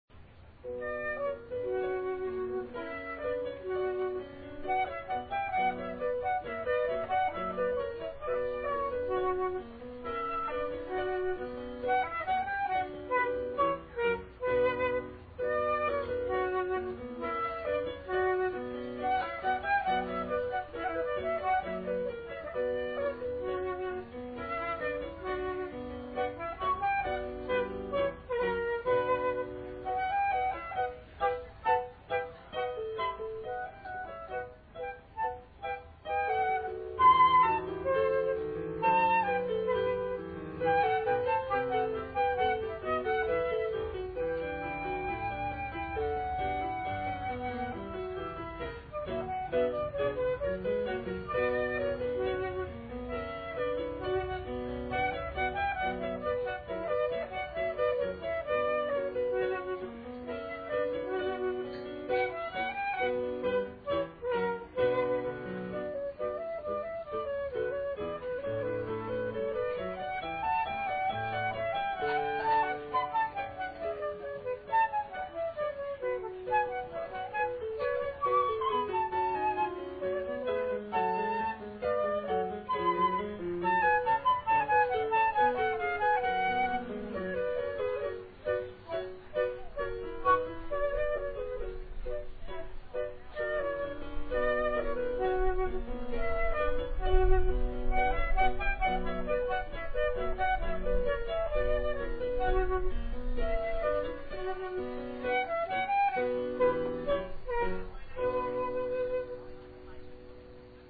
Classical Music Festival
Part of the Arts and Wine Festival
Courthouse Park, Cortland, NY USA
flute
piano